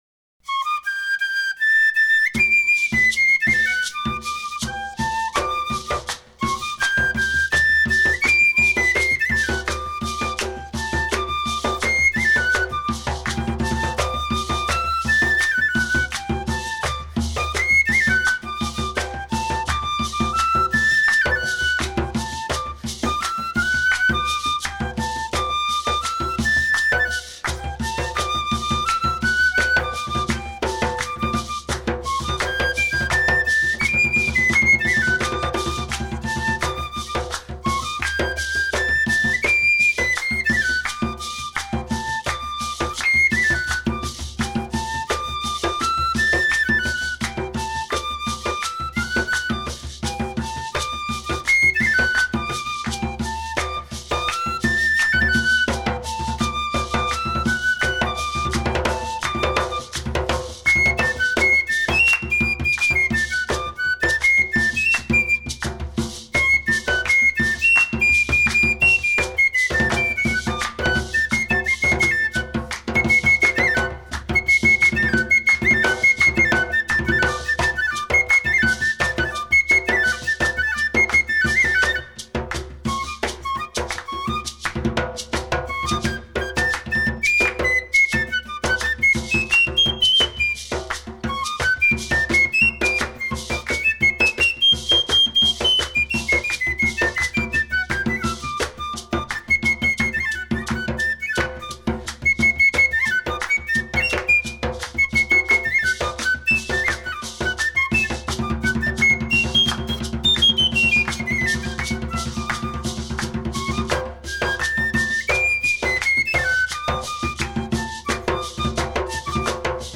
Colombiana